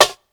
Snares
Sylense_Traditonal-NepSnr.wav